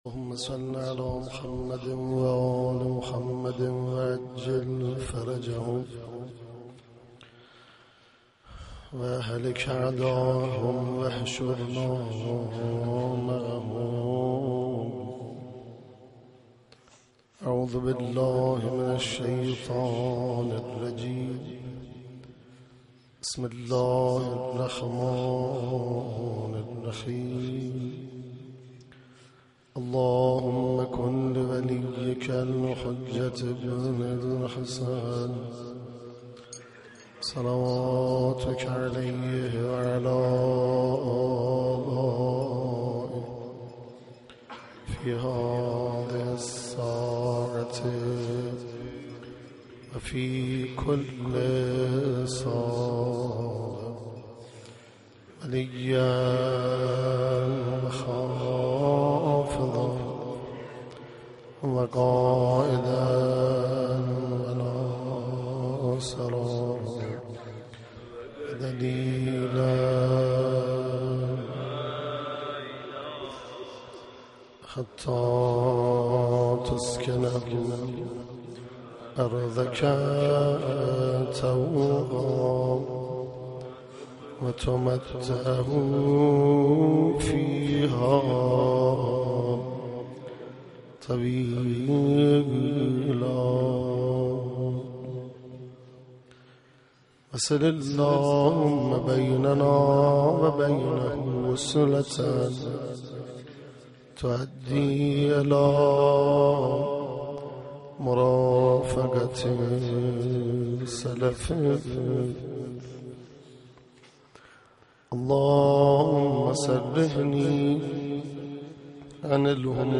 (بشنوید) روضه حضرت عباس(ع)